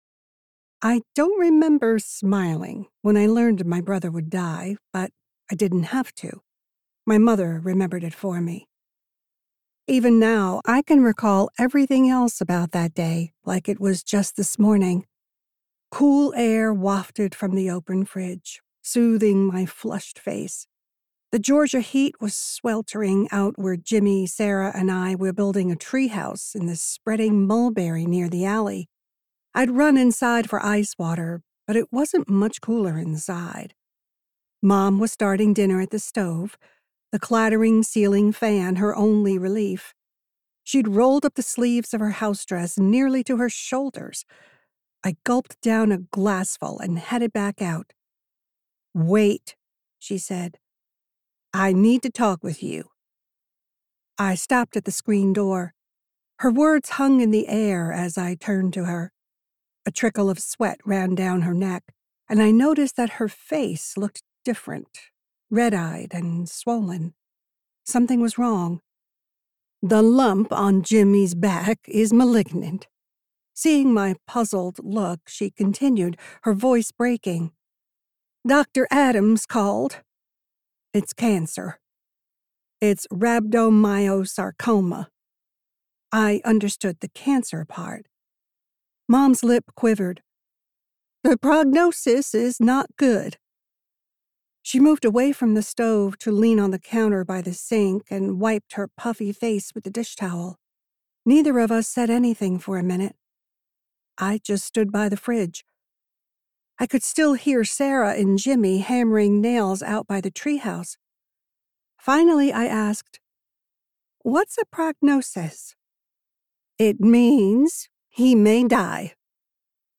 • Audiobook • 8 hrs, 39 mins